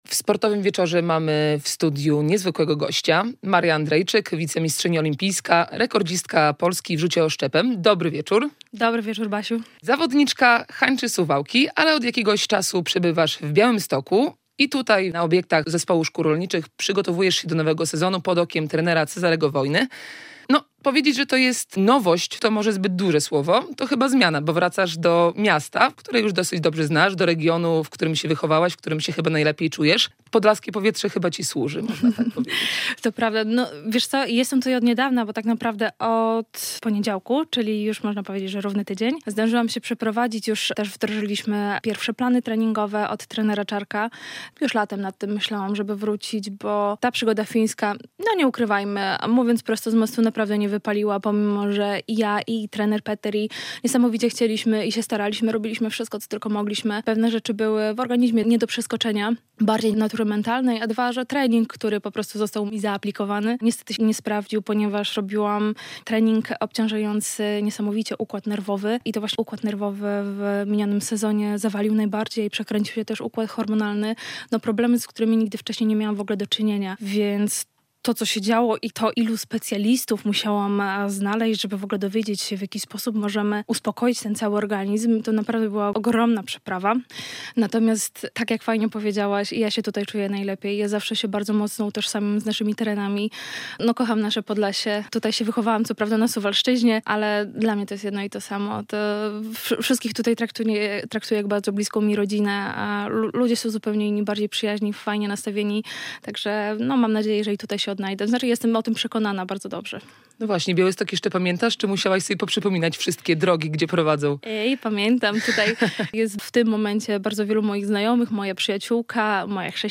Radio Białystok | Gość | Maria Andrejczyk [wideo] - oszczepniczka, wicemistrzyni olimpijska z Tokio